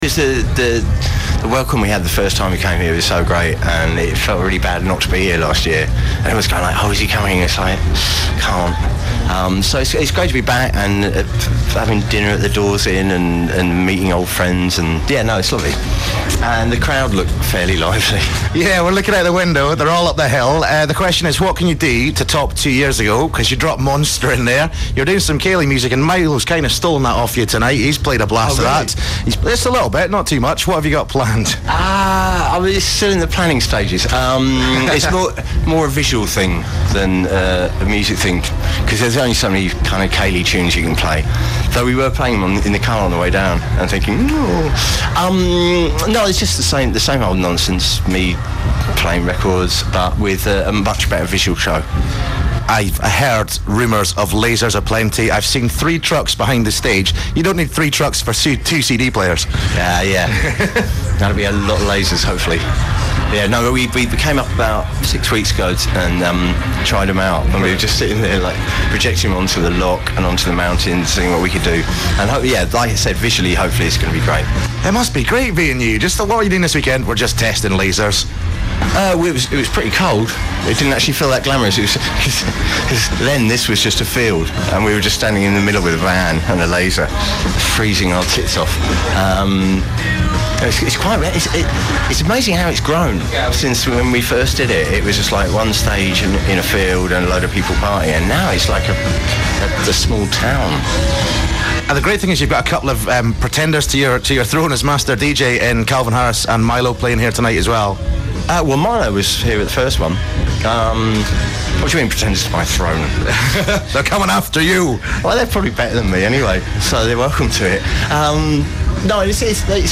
Source: XFM Scotland Radio (streaming broadcast) Duration
Bonus: interview before the show (an extract catched by chance on the fly ;) )